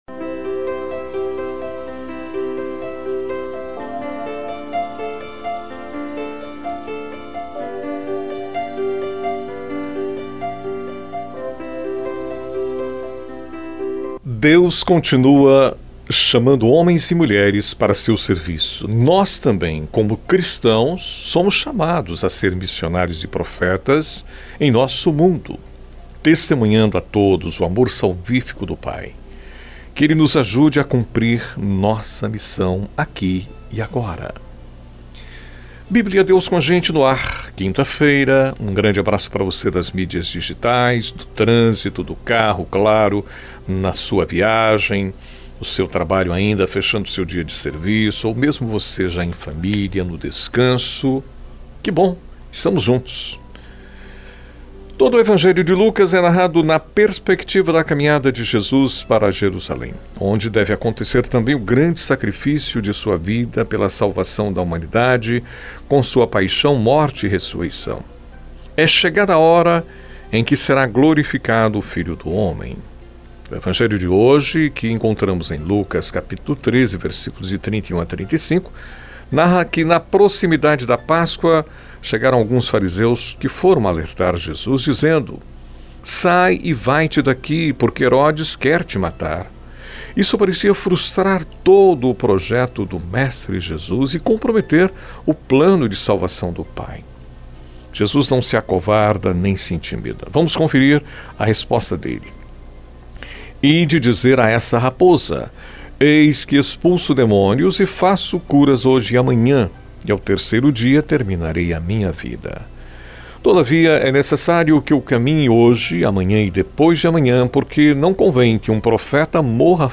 É um momento de reflexão diário com duração de aproximadamente 5 minutos